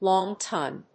/ˈlɔŋtʌn(米国英語), ˈlɔ:ŋtʌn(英国英語)/